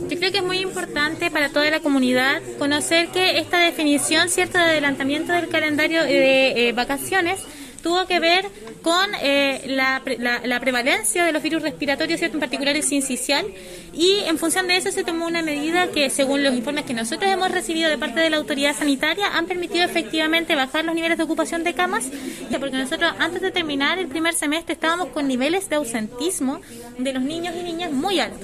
Al respecto, la Seremi de Educación de la Región de Los Lagos, Daniela Carvacho, hizo precisiones respecto del adelantamiento de las vacaciones, que según dijo, tuvieron más que ver con el virus sincicial que con el COVID-19